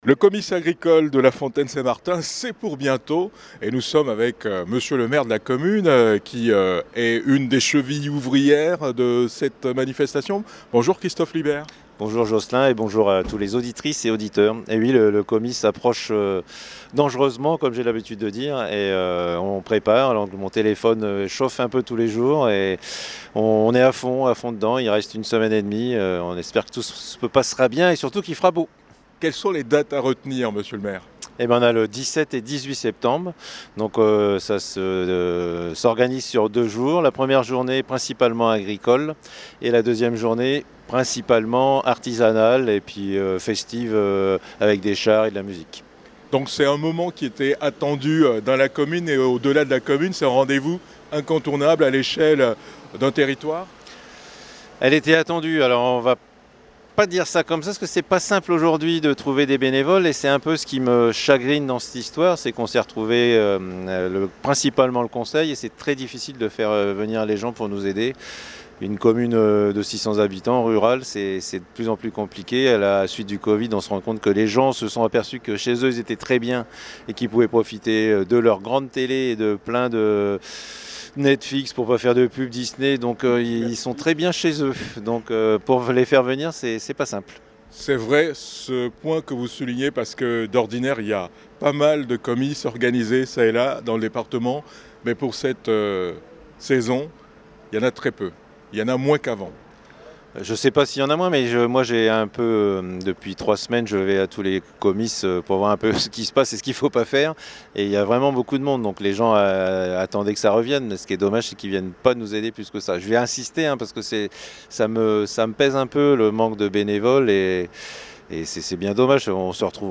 Le comice agricole artisanal et commercial de l’ancien canton de Pontvallain se déroule les 17 et 18 septembre 2022 à La Fontaine-St-Martin. Christophe Libert, maire de la commune, présente le programme.